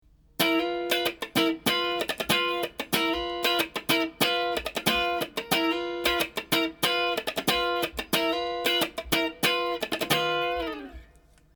オブリカードあり
カッティングしているだけのフレーズが①、カッティングにスライド・ブラッシングを加えて弾いているのが②です。
②の方がグルーブ感があり、フレーズとしても完成されているような印象を受けると思います。